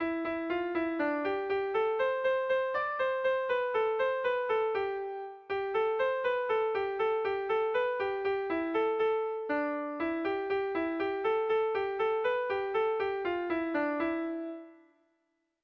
Gregorianoa
ABD